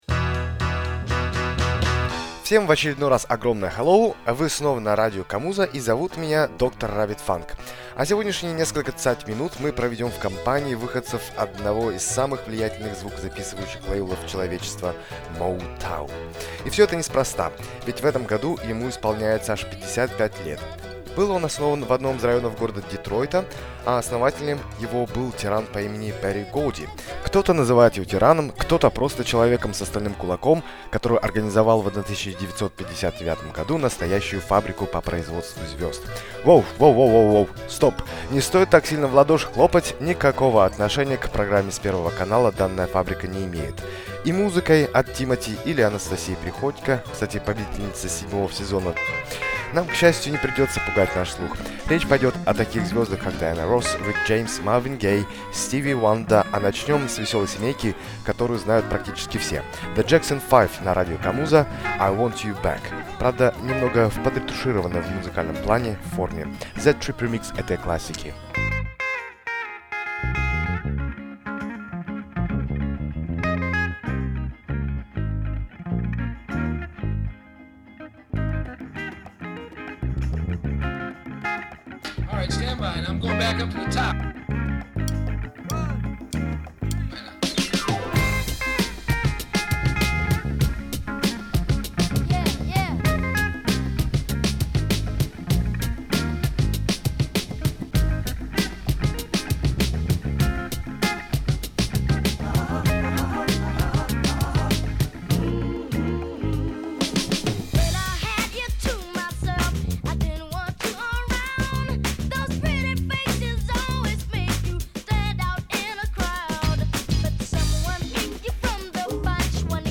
Согревающая музыка